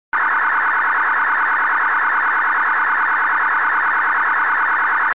Ширина спектра (Band Width) 1200 Hz
Частота манипуляции (Baud Rate) 1200 Hz
Несущих / спектральных полос (Count of Carriers) 2 с жестко фиксированными значениями 1200 и 1800 Hz, фиксация обеспечивается используемой модуляцией NFM
Разнос между несущими / полосами (Step between carriers) 600 Hz
Модуляция, в которой сигнал принят (RX mode) NFM
Образец MPT-1327, управляющий канал (81.5 Kb)